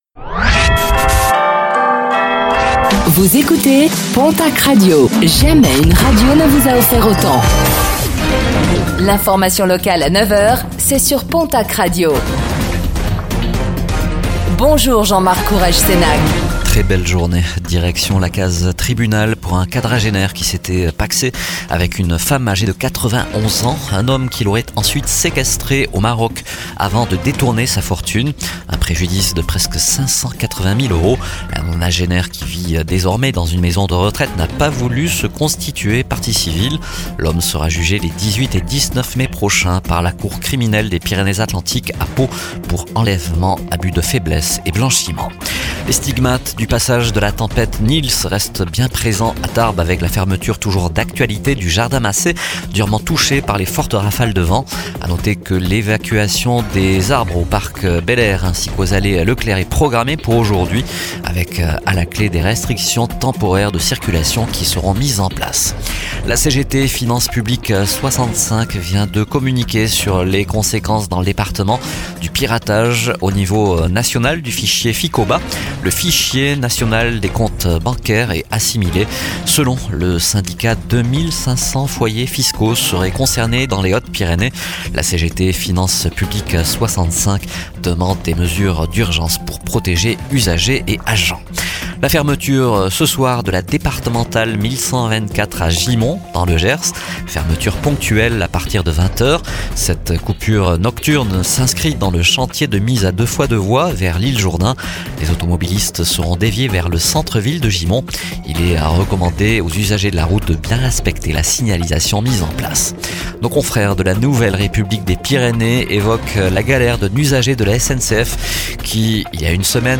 Infos | Mercredi 25 février 2026